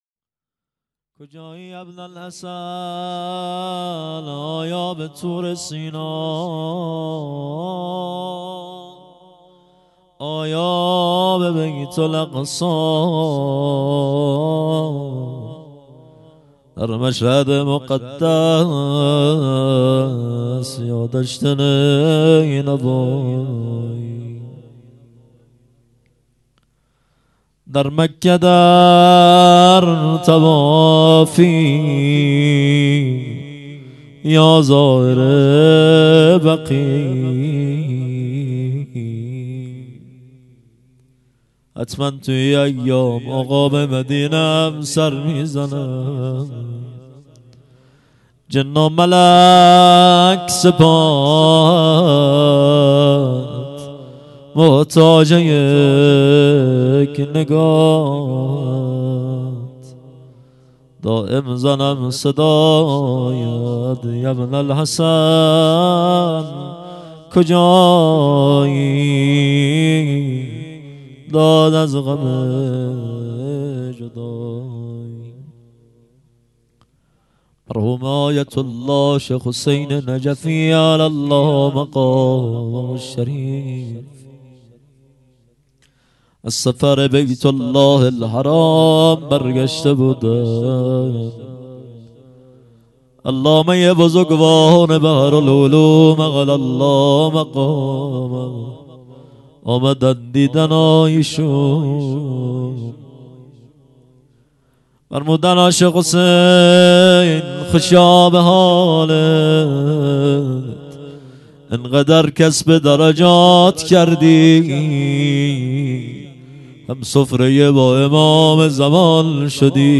هیئت مکتب الزهرا(س)دارالعباده یزد